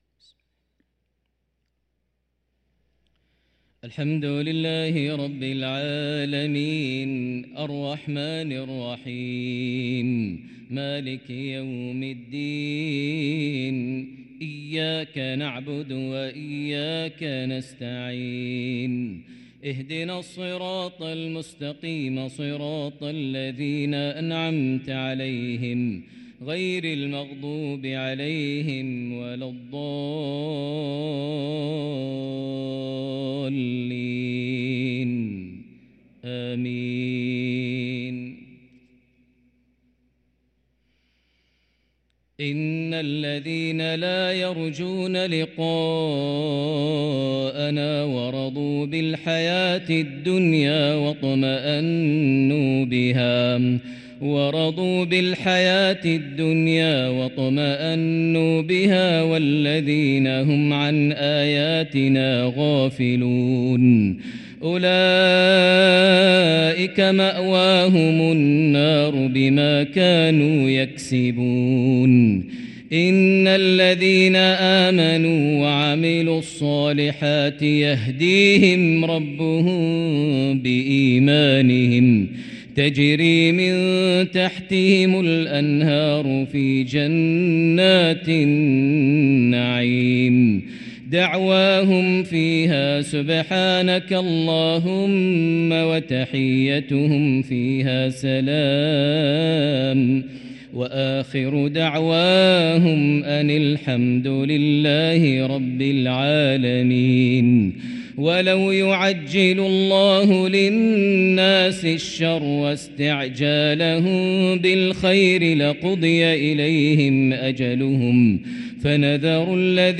صلاة العشاء للقارئ ماهر المعيقلي 28 شعبان 1444 هـ
تِلَاوَات الْحَرَمَيْن .